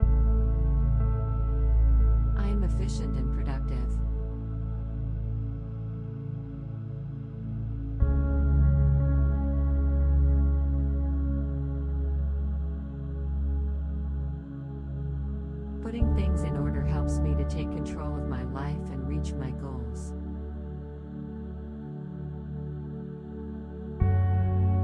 2. Listen to the calming music as you repeat the positive affirmations about staying organized.
This 5 minute guided mediation will help you to affirm your ability to stay declutter and stay organized.